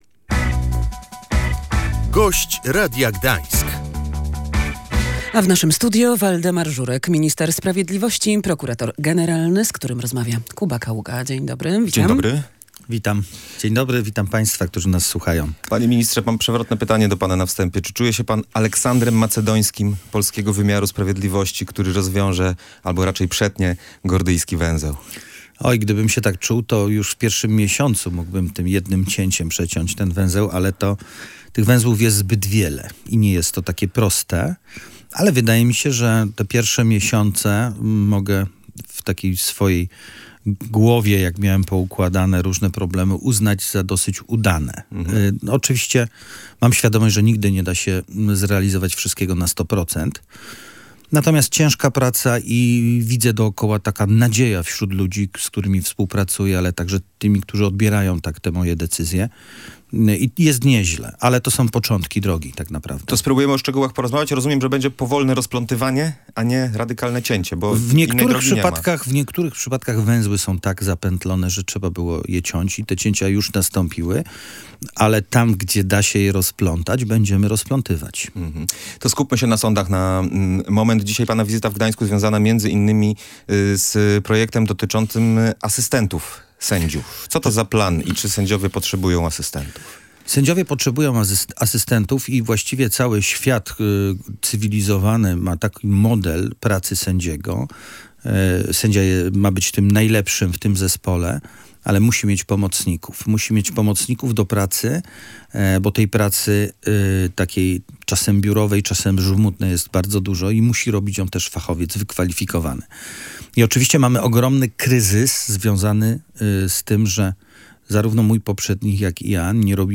Filia Krajowej Szkoły Sędziów i Prokuratorów w Gdańsku, mimo kłopotów z porozumieniem w tej sprawie z Uniwersytetem Gdańskim, zgodnie z planem zacznie działać w marcu 2026 roku. Waldemar Żurek, minister sprawiedliwości i prokurator generalny, zdradził w Radiu Gdańsk, że umowa na wynajęcie konkretnego obiektu jest już podpisana.